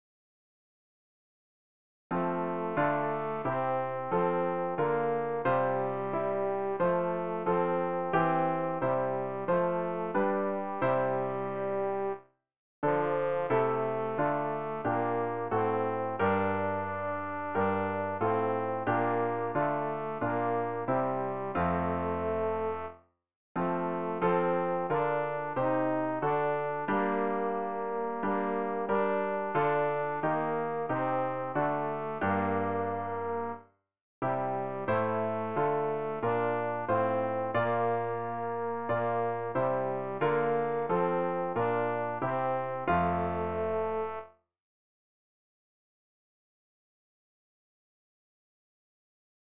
rg-820-wir-schauen-aus-tenor.mp3